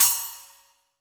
Index of /90_sSampleCDs/AKAI S6000 CD-ROM - Volume 3/Crash_Cymbal2/CHINA&SPLASH
M8SPLASH  -S.WAV